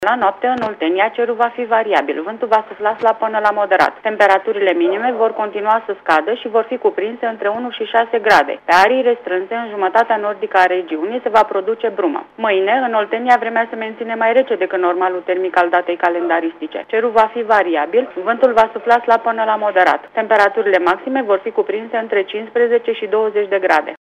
Meteo